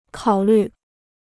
考虑 (kǎo lǜ) Dictionnaire chinois gratuit